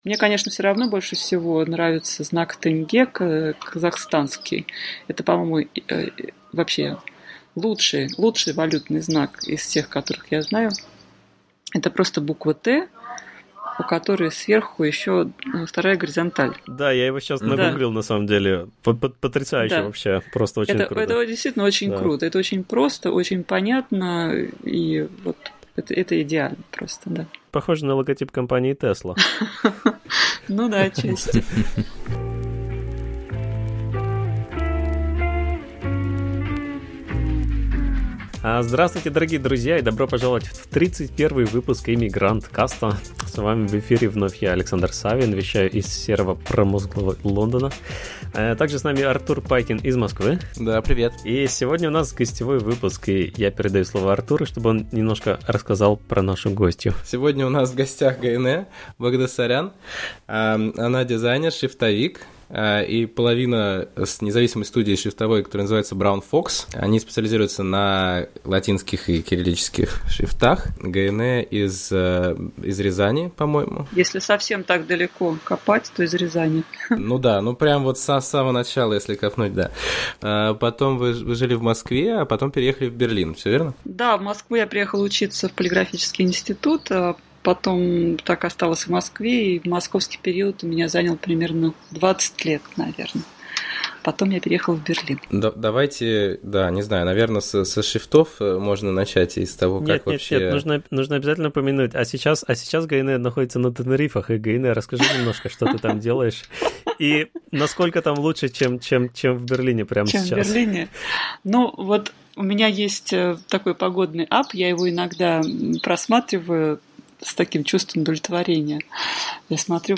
Гостевой выпуск Иммигранткаста